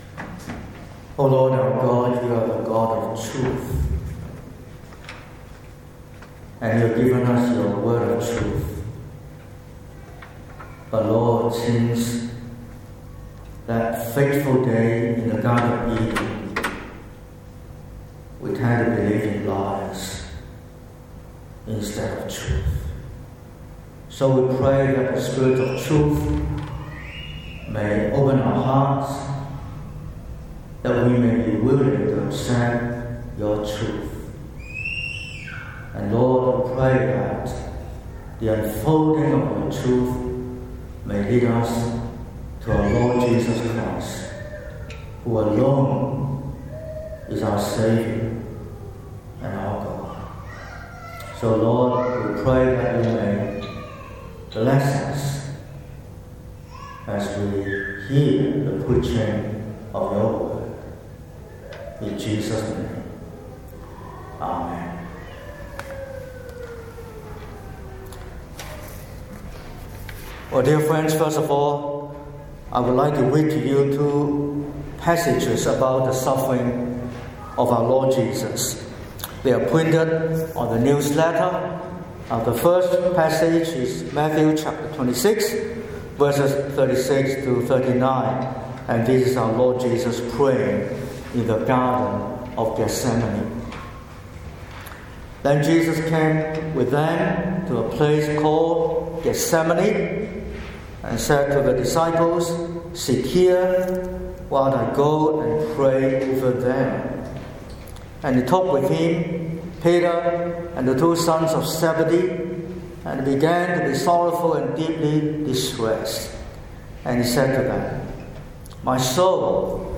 12/04/2026 – Morning Service: The wrath of God and the salvation by Christ’s love